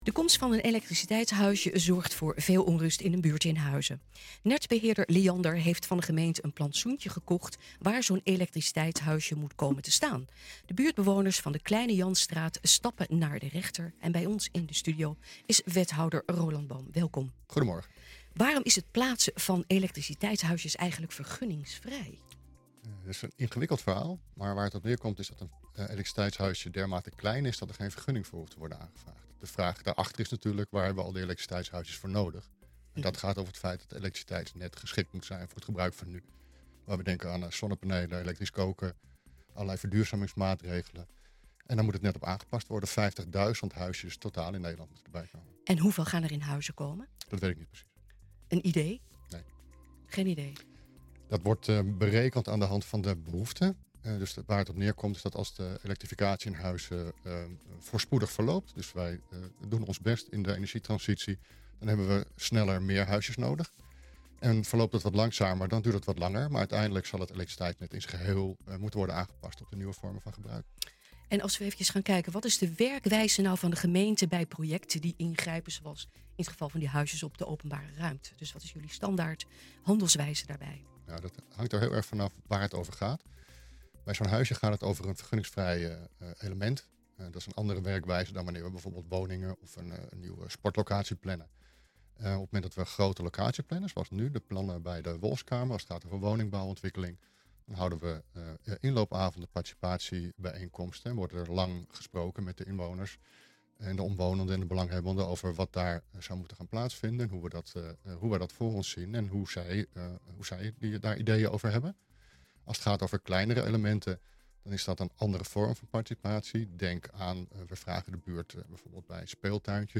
Bij ons in de studio is wethouder Roland Boom.